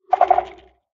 sculk_clicking3.ogg